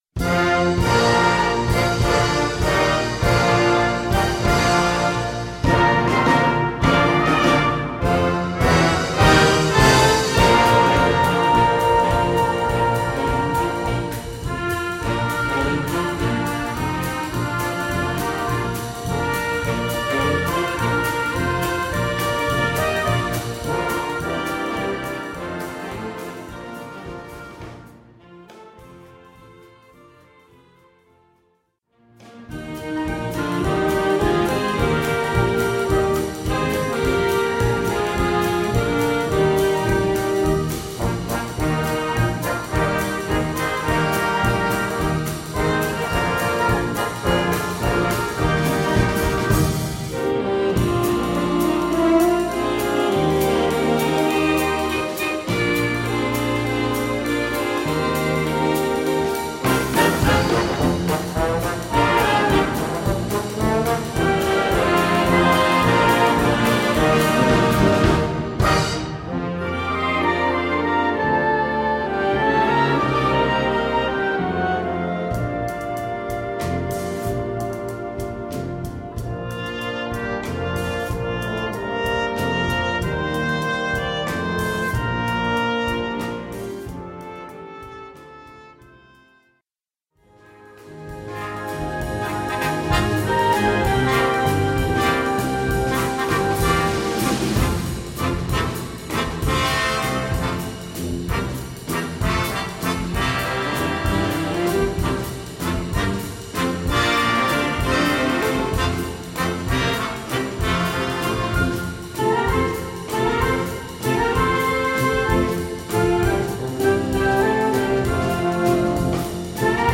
Gattung: Medley
6:20 Minuten Besetzung: Blasorchester Tonprobe